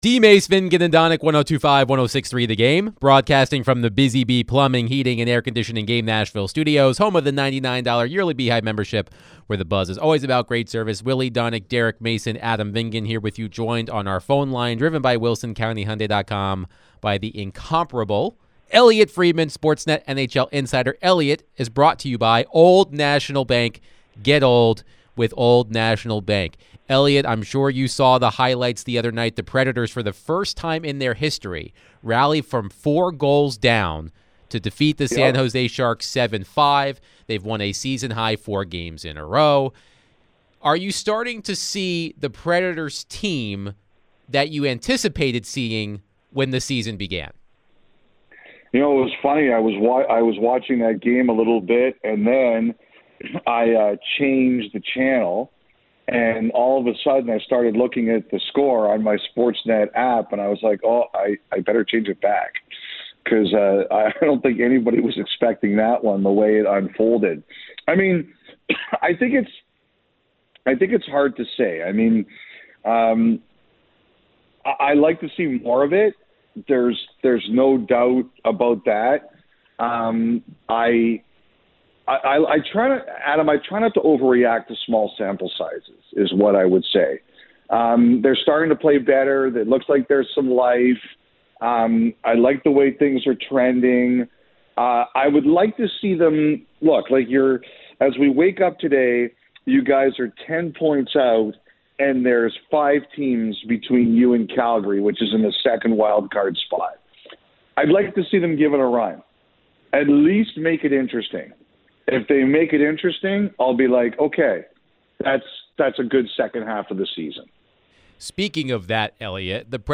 NHL Insider Elliotte Friedman joined DVD to discuss all things around the NHL, Nashville Predators and more